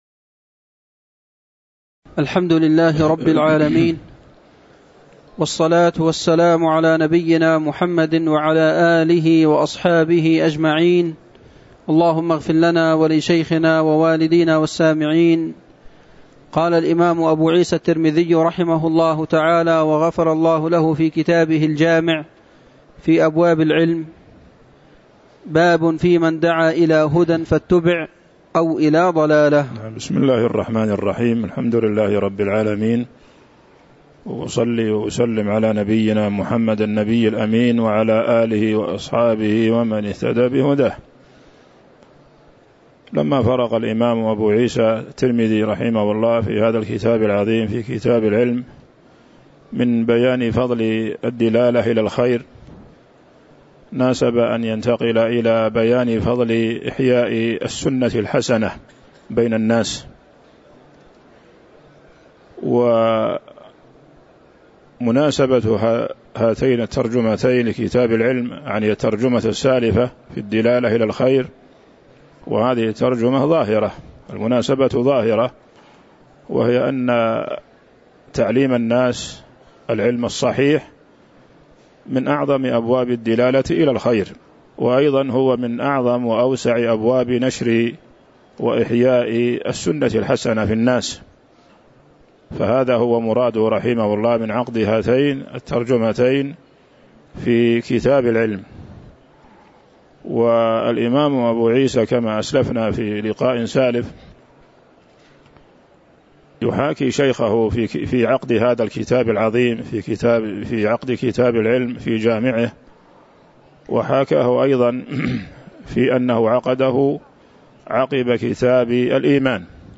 تاريخ النشر ٢٦ شوال ١٤٤٤ هـ المكان: المسجد النبوي الشيخ